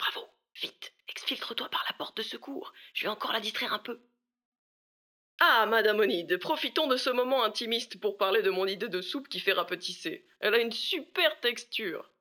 VO_LVL3_EVENT_Bravo reussite mission_03.ogg